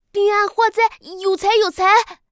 序章与第一章配音资产
c01_6卖艺小孩C_1.wav